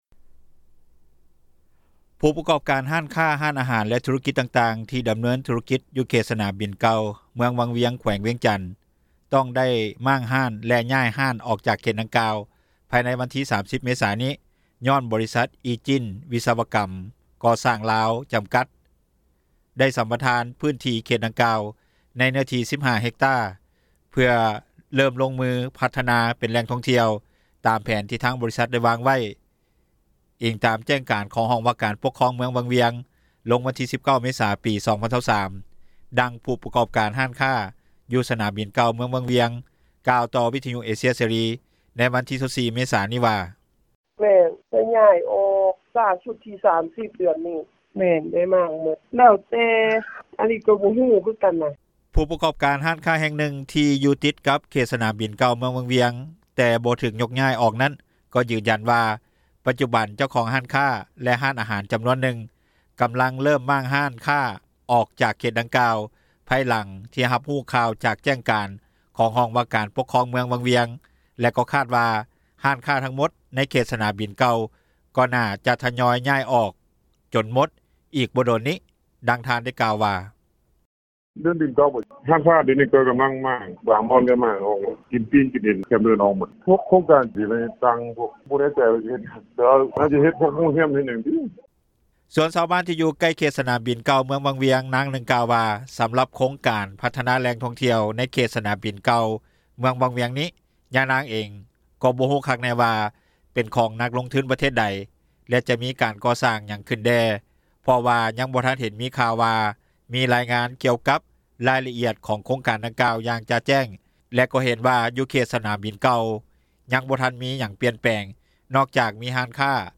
ດັ່ງຜູ້ປະກອບການ ຮ້ານຄ້າ ຢູ່ສນາມບິນເກົ່າເມືອງ ວັງວຽງກ່າວ ຕໍ່ວິທຍຸເອເຊັຽເສຣີ ໃນວັນທີ 24 ເມສາ ນີ້ວ່າ:
ດັ່ງຍານາງກ່າວວ່າ: